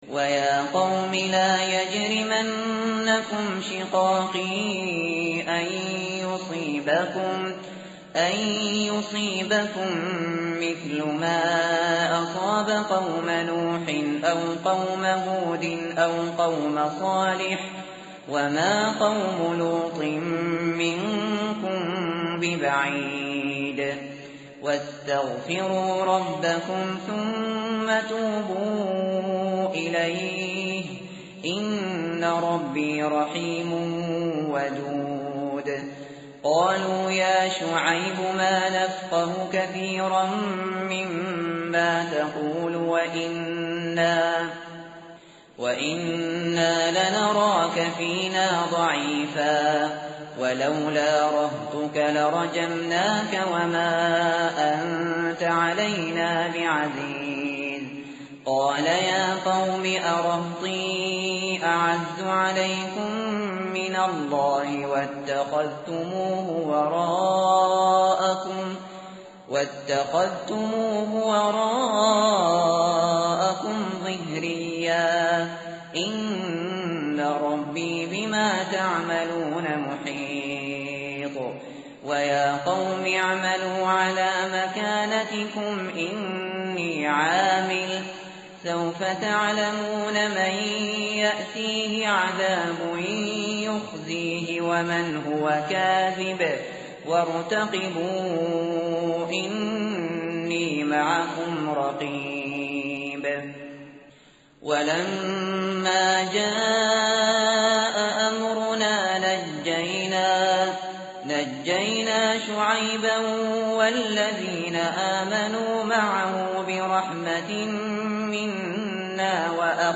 tartil_shateri_page_232.mp3